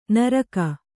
♪ naraka